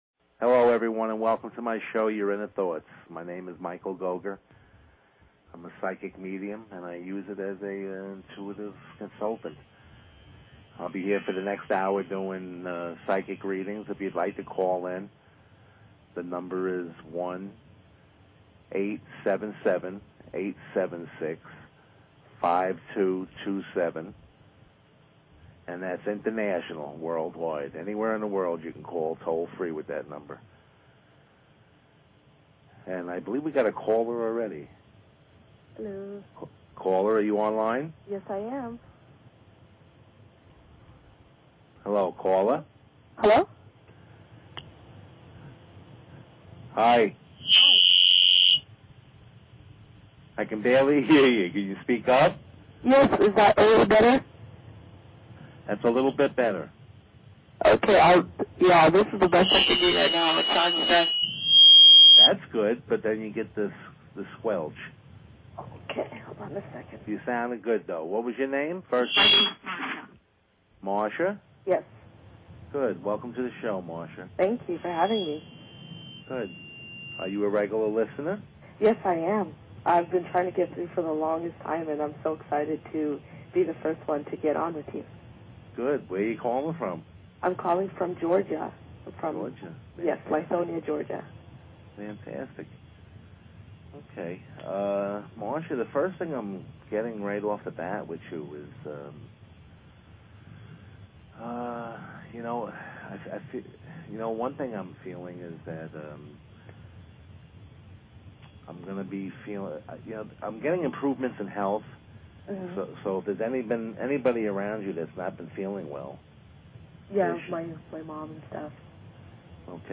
Talk Show Episode, Audio Podcast, Your_Inner_Thoughts and Courtesy of BBS Radio on , show guests , about , categorized as
Hello and welcome to my radio show Your Inner Thoughts.